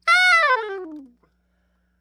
SOPRANO FALL
SOP SHRT F 5.wav